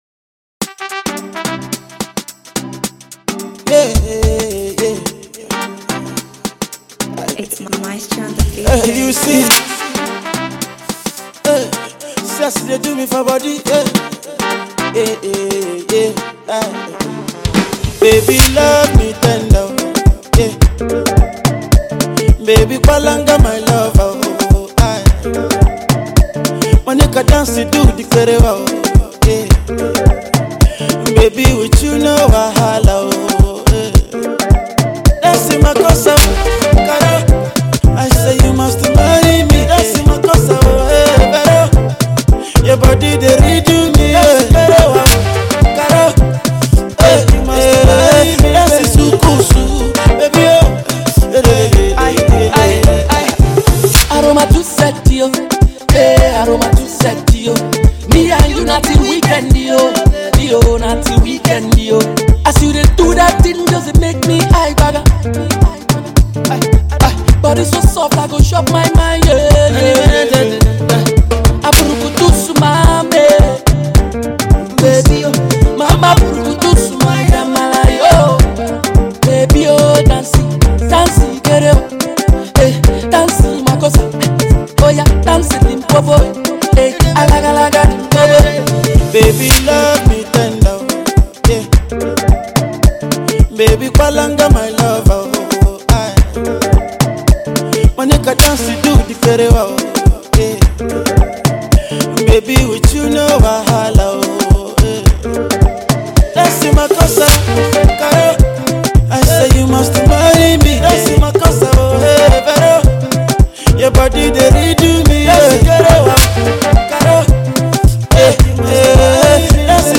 It is a mid-tempo